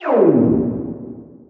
fail.ogg